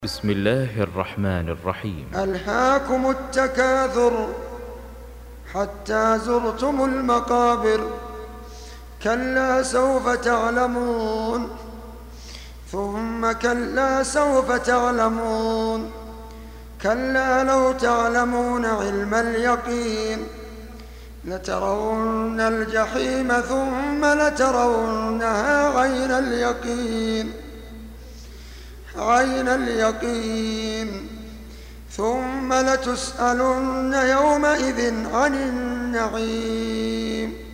Surah At-Tak�thur سورة التكاثر Audio Quran Tarteel Recitation
Surah Sequence تتابع السورة Download Surah حمّل السورة Reciting Murattalah Audio for 102.